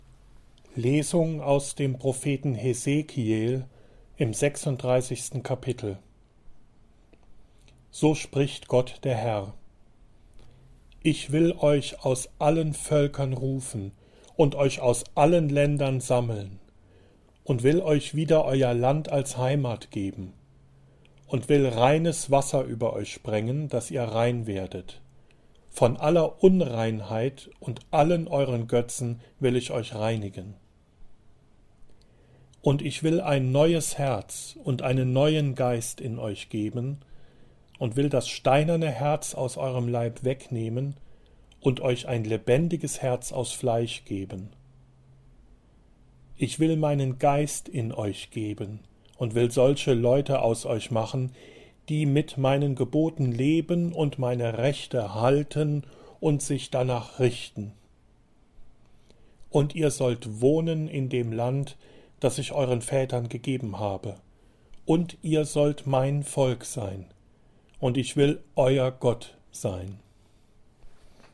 Lesungen und Evangelien